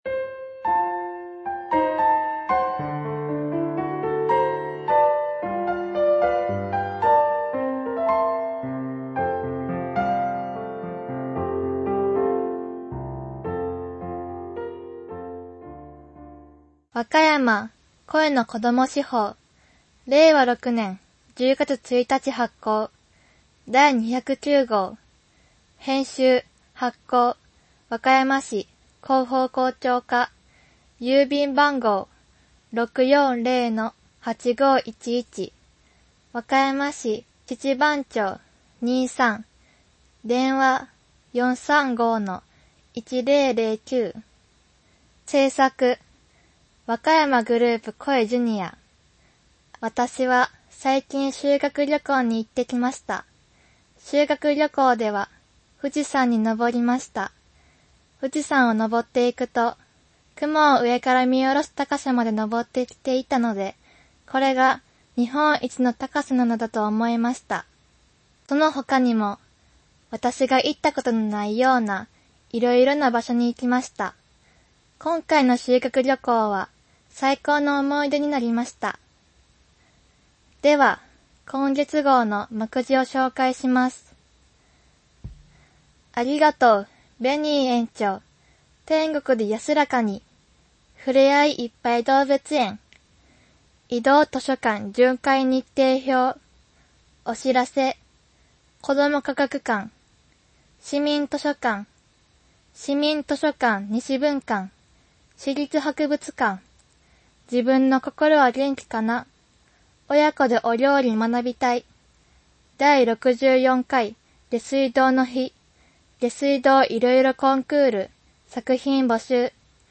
～ クロスワード 前回のこたえとクイズ当選者発表 さくひんてん この本おもしゃいでぇ（図書紹介） わかやまこども市報 No.209 （PDF 3.8MB） わかやまこども市報 No.209【音声版】 （mp3 7.8MB） PDF形式のファイルをご利用するためには，「Adobe（R） Reader」が必要です。